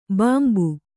♪ bāmbu